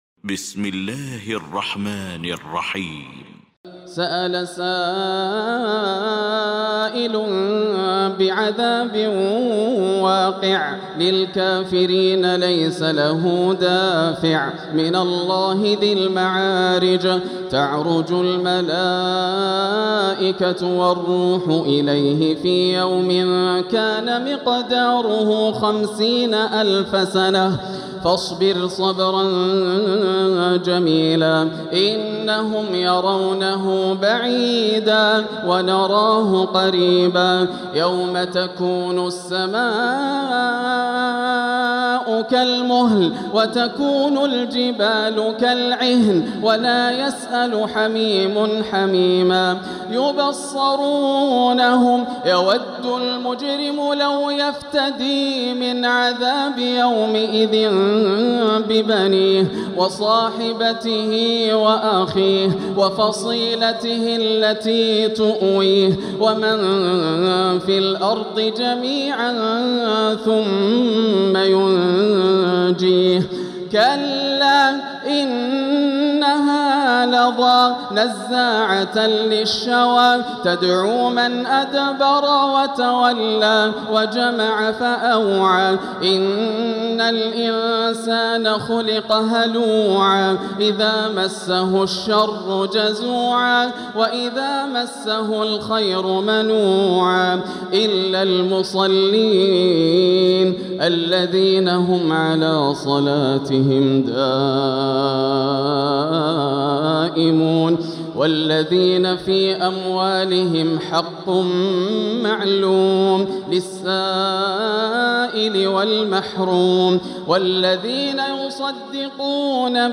المكان: المسجد الحرام الشيخ: فضيلة الشيخ ياسر الدوسري فضيلة الشيخ ياسر الدوسري المعارج